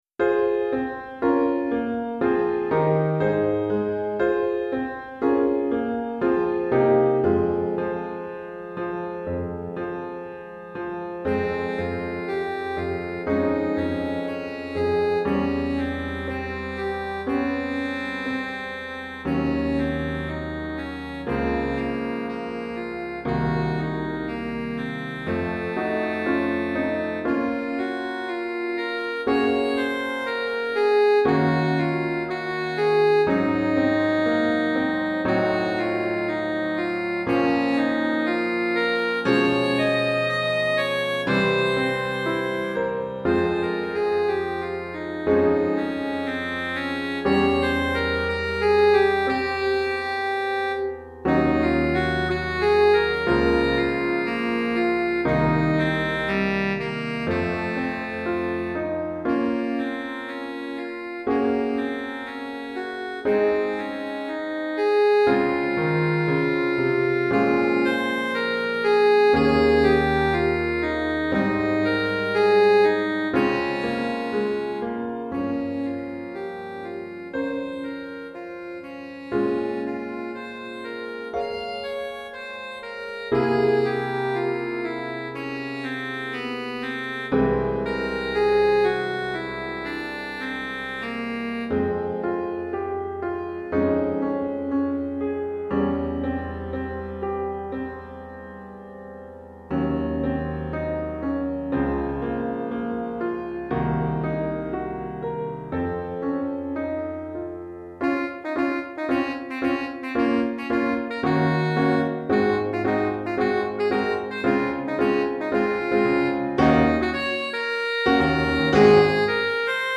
Saxophone et Piano